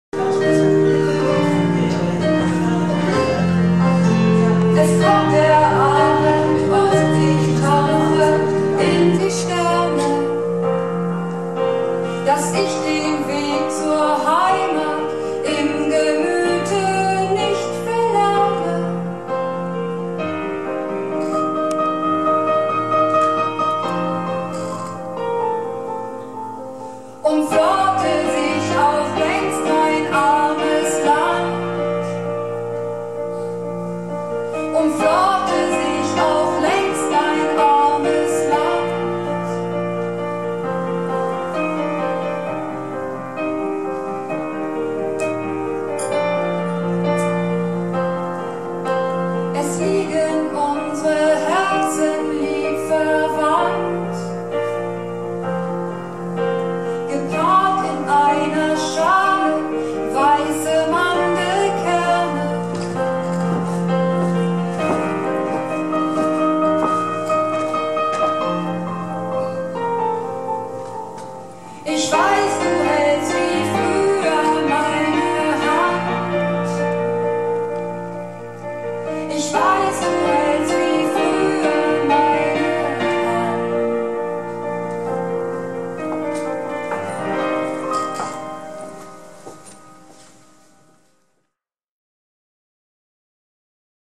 Theater - "Du musst es wagen - Sünner Tegenstöten word nümms deftig" am 18.03.2012 in Emden